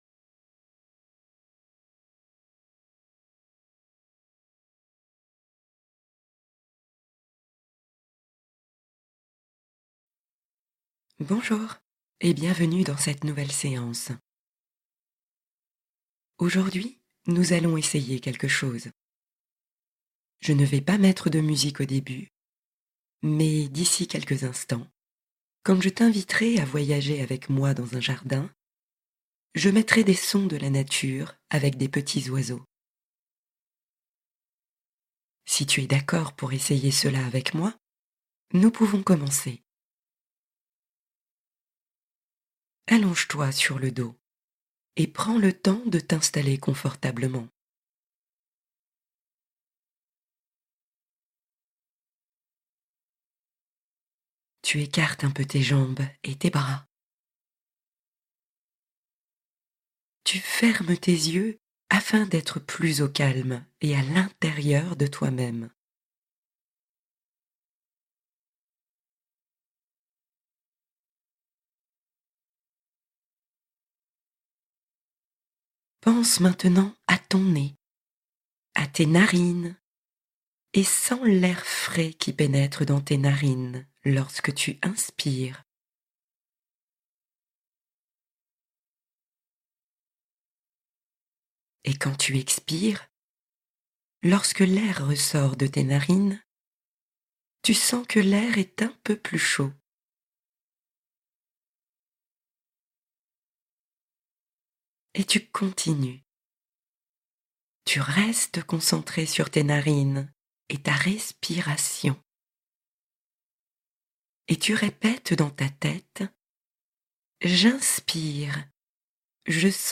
Réconfort Solaire : Méditation douce pour toute la famille